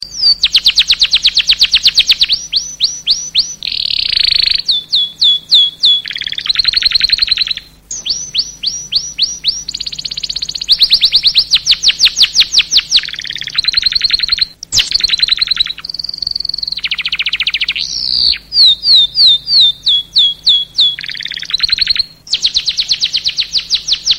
Nada dering Burung Kenari untuk driver Grab, Gojek…
Keterangan: Buat suasana terima orderan lebih semangat dengan Nada Dering Burung Kenari MP3! Nada dering ini membawa suara kicau burung kenari yang ceria dan menyenangkan, pas banget buat kamu para driver Grab dan Gojek.
nada-dering-burung-kenari-untuk-driver-grab-gojek-id-www_tiengdong_com.mp3